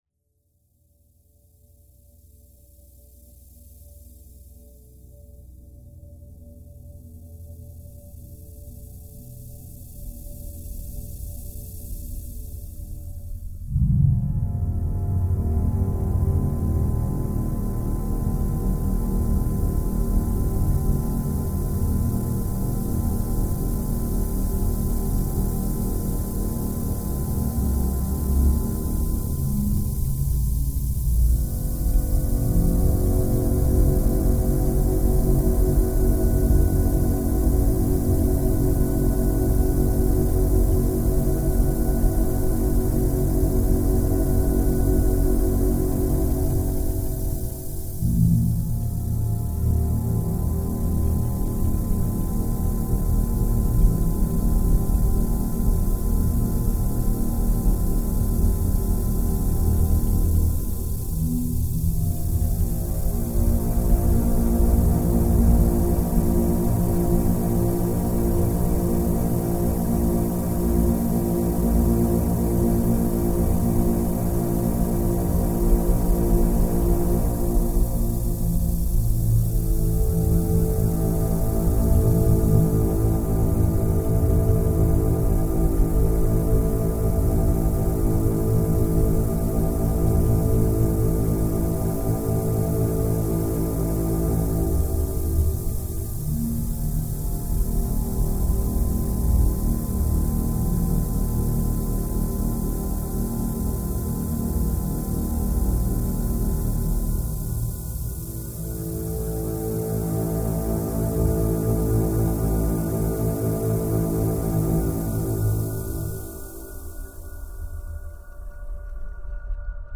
File under: Ambient / Dark Ambient